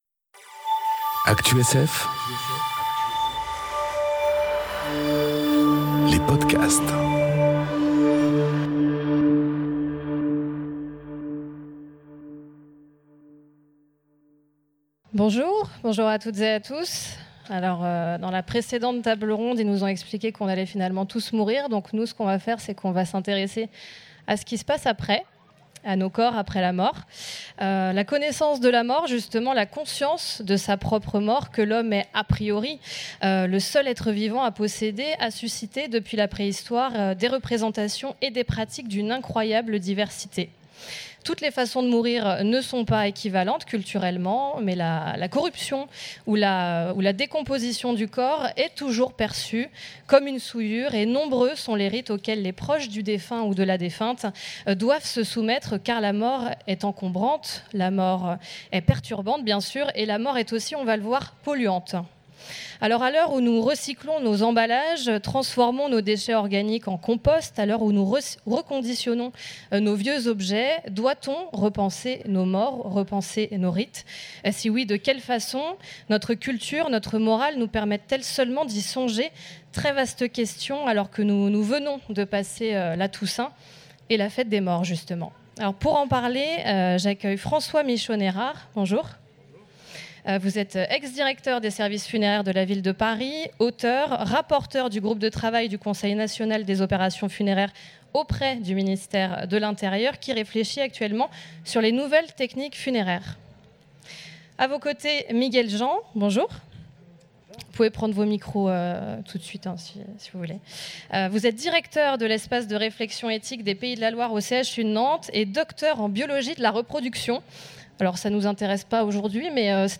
Conférence Recycler le corps : entre sacré et imaginaire enregistrée aux Utopiales 2018